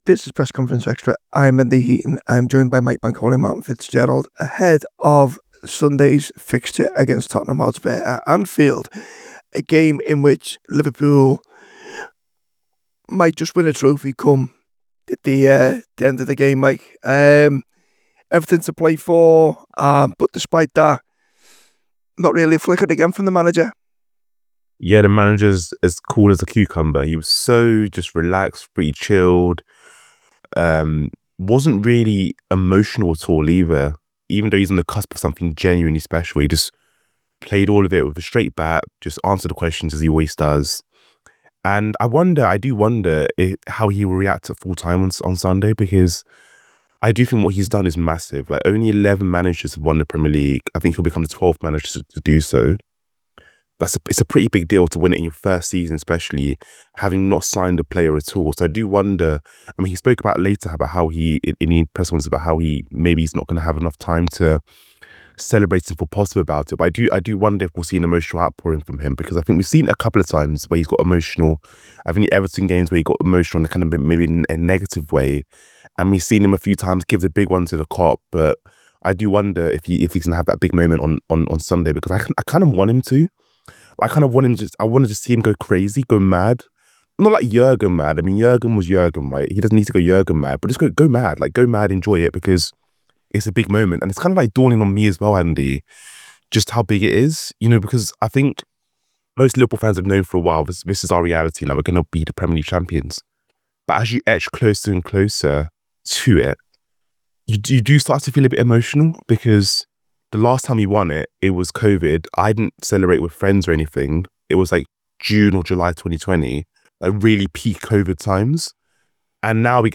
Below is a clip from the show – subscribe for more on the Liverpool v Tottenham press conference…